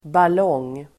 Uttal: [bal'ång:]